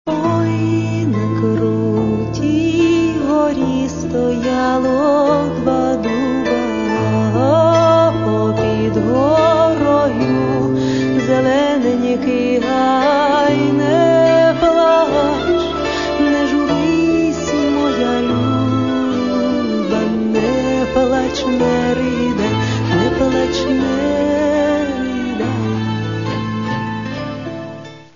в прозрачных, нежных, женственных его проявлениях